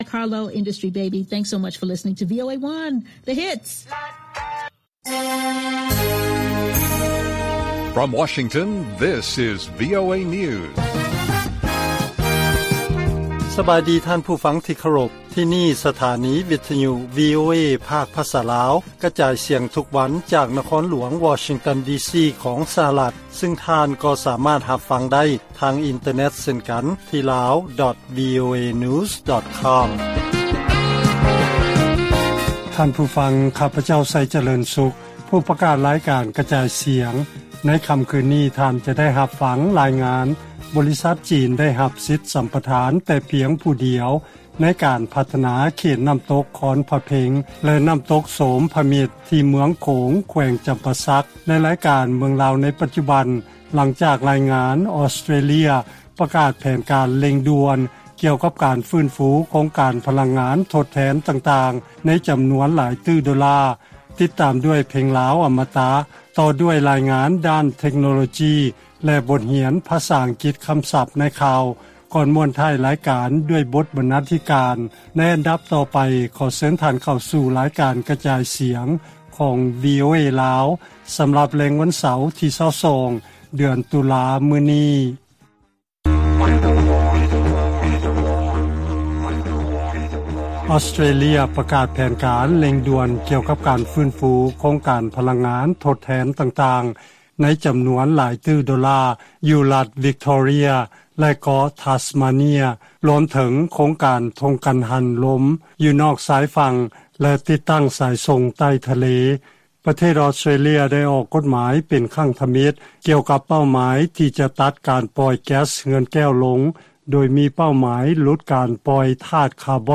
ລາຍການກະຈາຍສຽງຂອງວີໂອເອລາວ: ອອສເຕຣເລຍ ສັນຍາຈະສ້າງໂຄງການພະລັງງານທົດແທນມູນຄ່າຫຼາຍຕື້ໂດລາ.